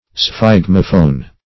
Search Result for " sphygmophone" : The Collaborative International Dictionary of English v.0.48: Sphygmophone \Sphyg"mo*phone\, n. [Gr.